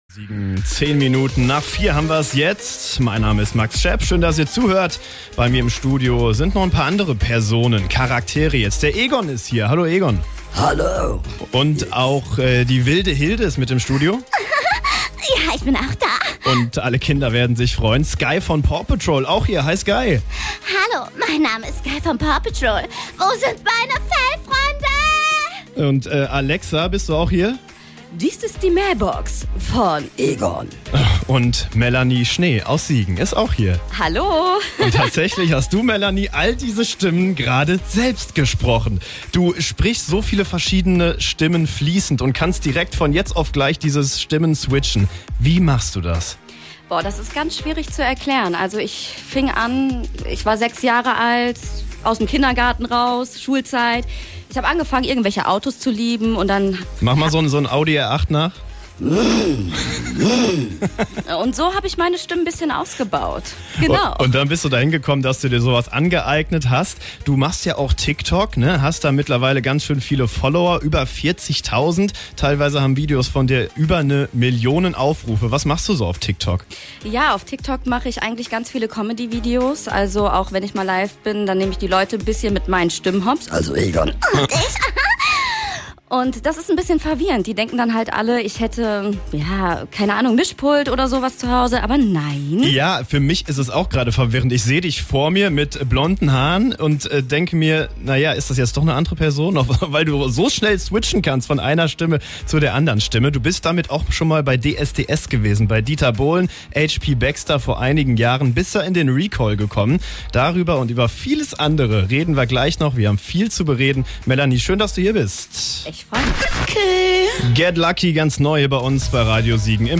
Die Siegenerin ist auf TikTok aktiv und spricht fünf verschiedene Stimmen fließend.
Unter anderem kann sie die Figur Sky der Kinderserie Paw Patrol nachsprechen.
5 Stimmen in einer Person